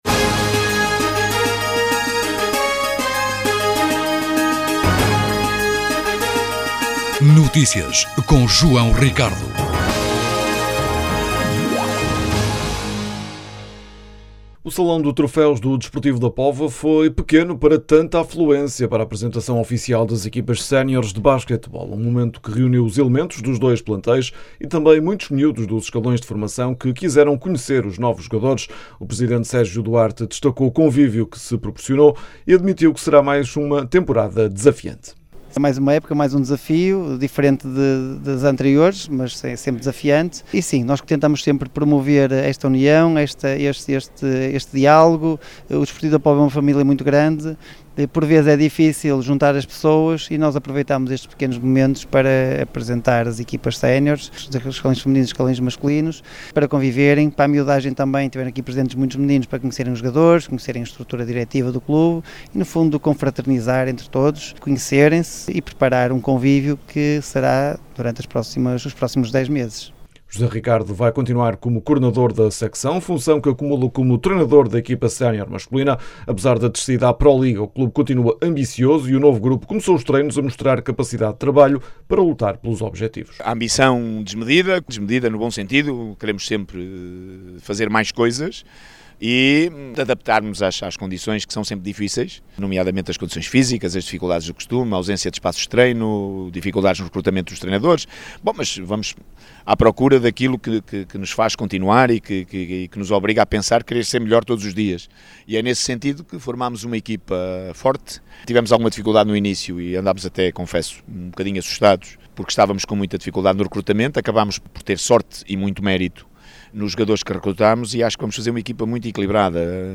O Salão de Troféus do CDP foi pequeno para tanta afluência para a apresentação oficial das equipas seniores de basquetebol.
As declarações podem ser ouvidas na edição local.